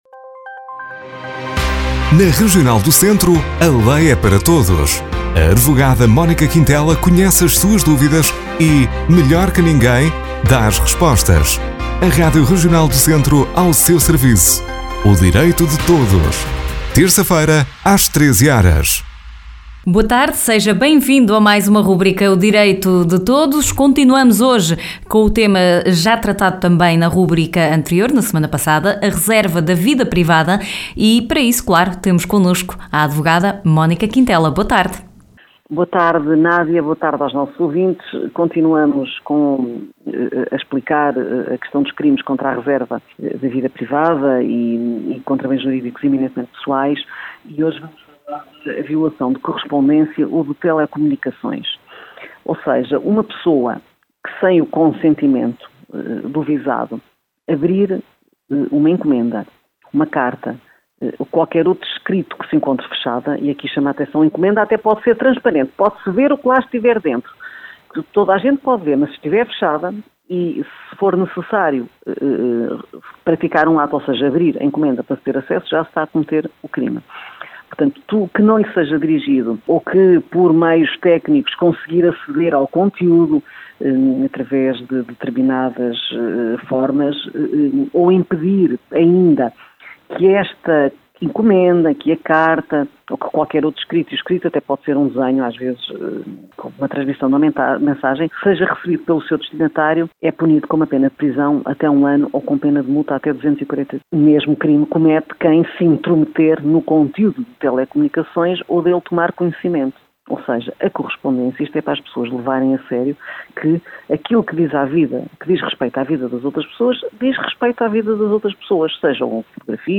Na rubrica de hoje a advogada Mónica Quintela fala da a reserva da vida privada e outros bens jurídicos pessoais (continuação da rubrica da semana passada).